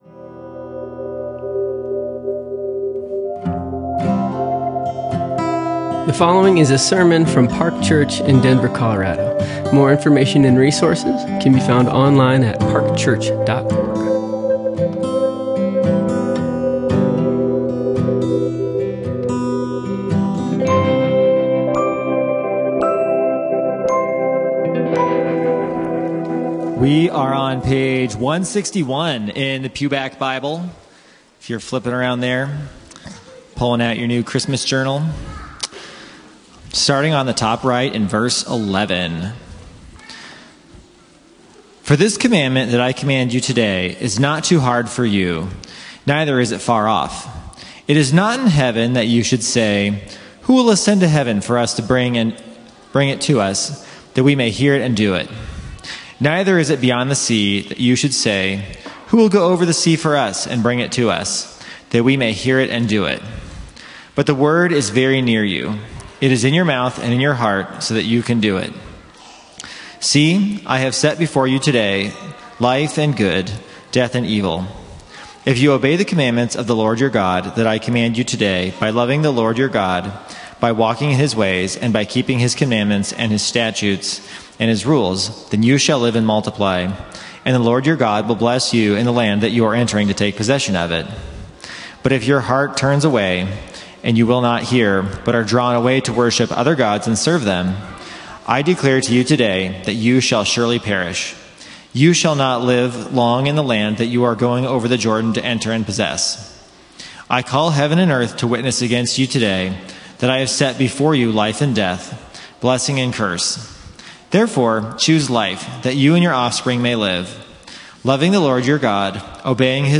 Each year, to finish the year, we have a Christmastide service of worship and prayer to look back on the previous year and seek to commit our way to the Lord in the upcoming year.